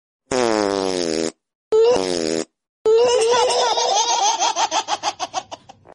meow meow song for kids